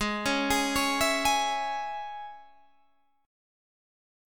Absus4#5 Chord